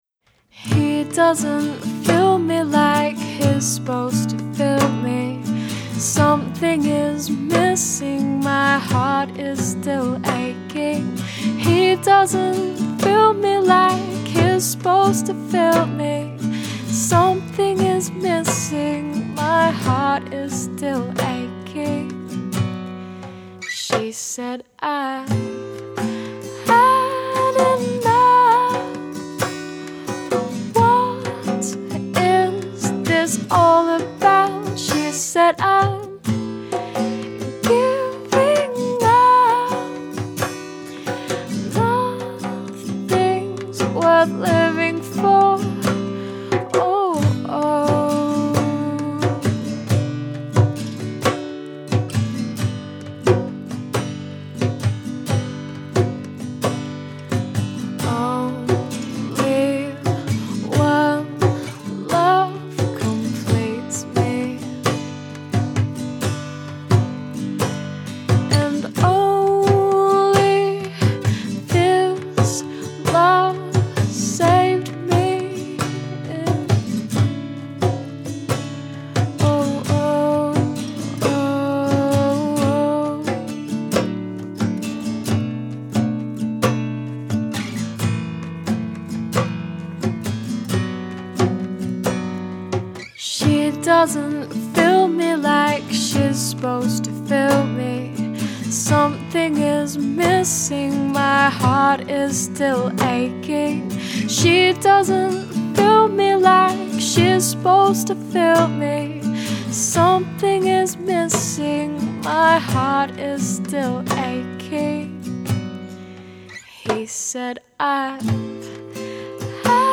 lead vocals/classical guitar.
acoustic bass/guitars/piano/backing vocals.
piano/vocals/percussion.